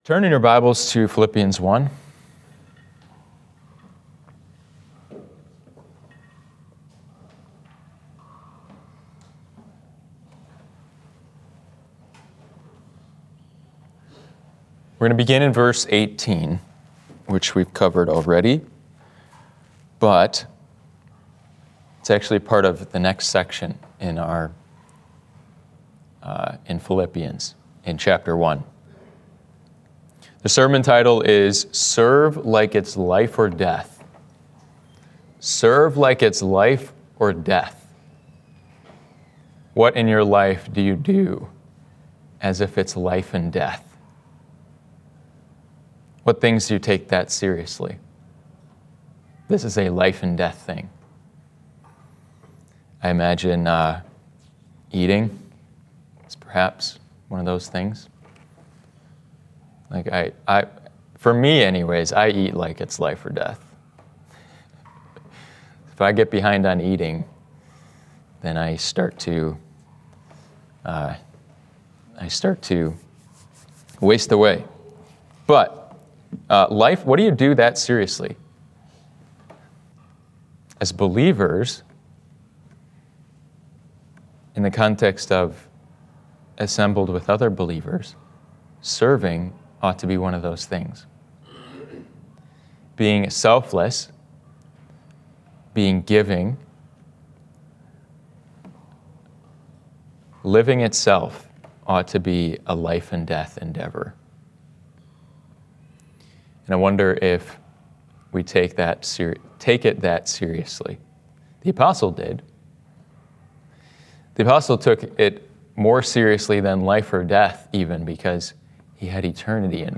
Serve Like It's Life or Death —Sunday AM Service— Passage: Philippians 1:18–26 Series: Letter to the Philippians–The Mind of the Messiah Preache ...